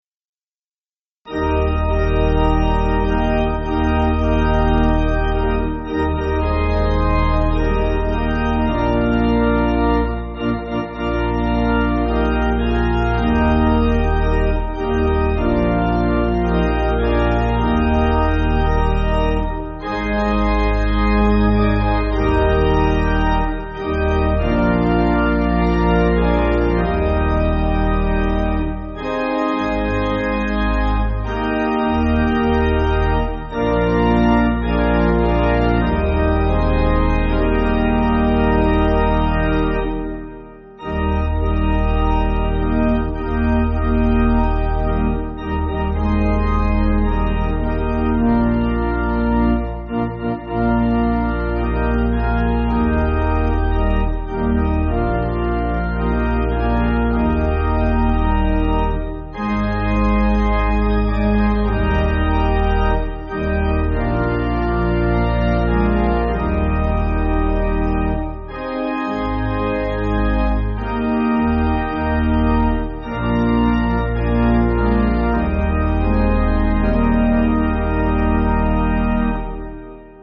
Organ
(CM)   4/Eb